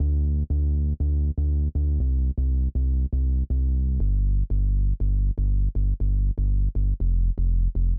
ITA Bass Riff D-C-G-F.wav